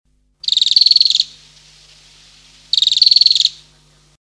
Este Furnárido está intrínsecamente ligado a los bosques templados de Chile, distribuyéndose desde Fray Jorge hasta Magallanes. Es un ave vocal y conspicua, y es generalmente reconocido por su llamado característico.
Aphrastura-Spinicauda-1-Rayadito.mp3